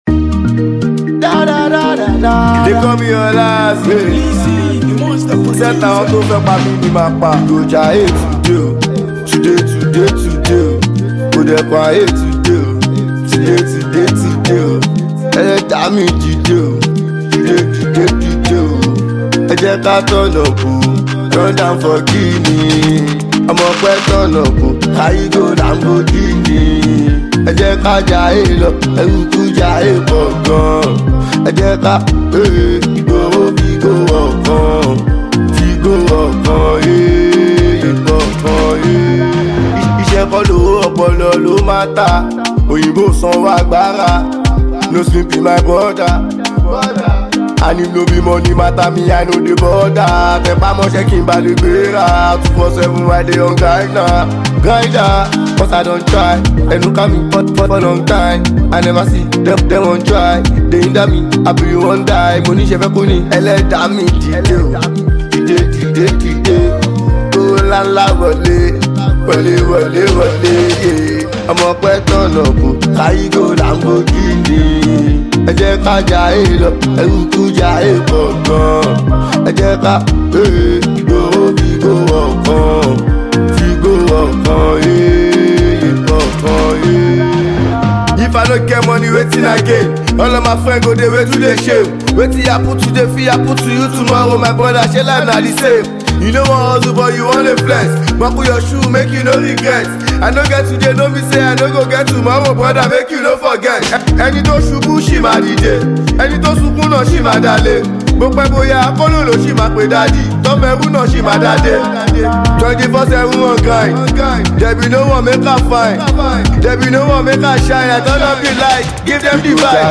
hip-hop song
the song will get you moving.